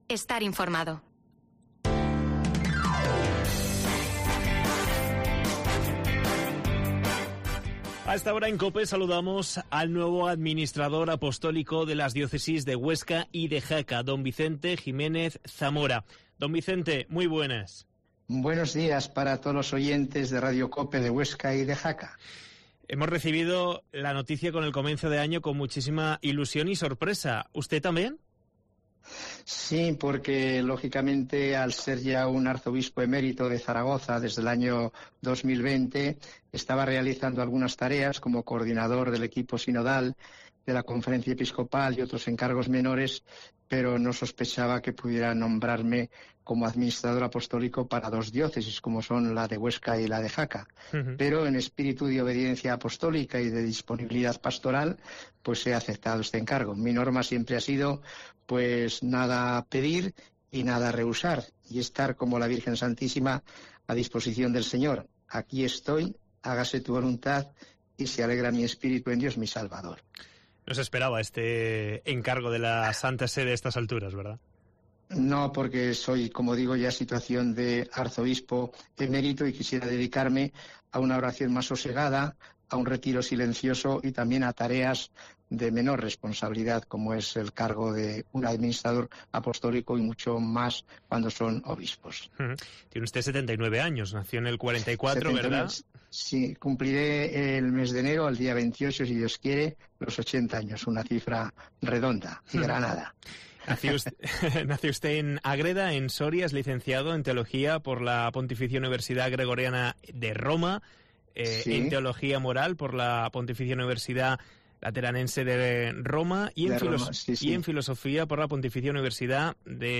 AUDIO: Entrevista al Administrador Apostólico de Huesca y de Jaca, Don Vicente Jimenez Zamora